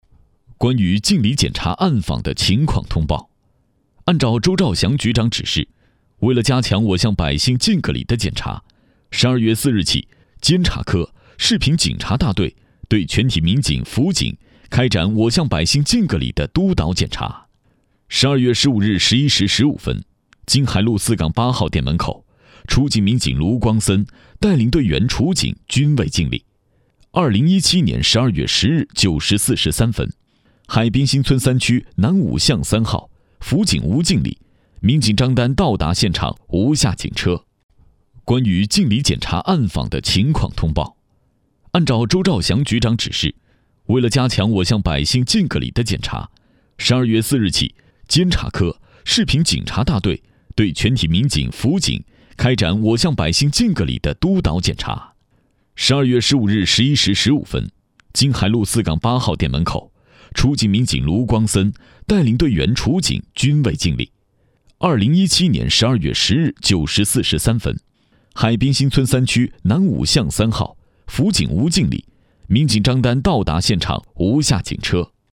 国语中年低沉 、大气浑厚磁性 、沉稳 、娓娓道来 、男纪录片 、100元/分钟男S346 国语 男声 纪录片音—历史韵味 低沉|大气浑厚磁性|沉稳|娓娓道来 - 样音试听_配音价格_找配音 - voice666配音网
国语中年低沉 、大气浑厚磁性 、沉稳 、娓娓道来 、男纪录片 、100元/分钟男S346 国语 男声 纪录片音—历史韵味 低沉|大气浑厚磁性|沉稳|娓娓道来